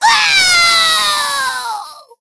nita_die_02.ogg